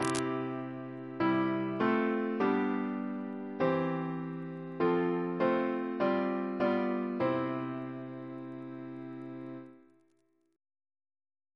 CCP: Chant sampler
Single chant in C Composer: Robert Prescott Stewart (1825-1894) Reference psalters: ACP: 184; OCB: 68; PP/SNCB: 194; RSCM: 210